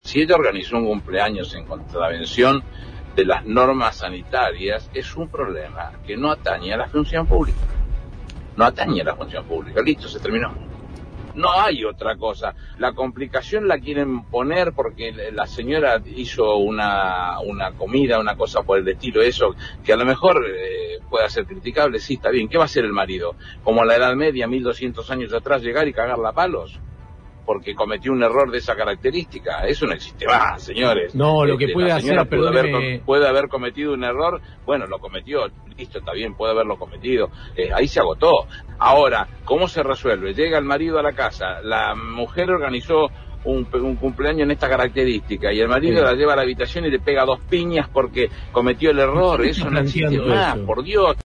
Y siguió: «La complicación la quieren poner porque la señora hizo una comida, que puede ser criticable. ¿Qué va a hacer el marido, como en la Edad Media, 1.200 años atrás, llegar y cagarla a palos porque cometió un error de esas características?», planteó el interventor de Yacimientos Carboníferos Río Turbio (YCRT), en declaraciones a Radio Con Vos.